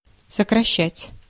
Ääntäminen
Tuntematon aksentti: IPA: /səkrɐˈɕːætʲ/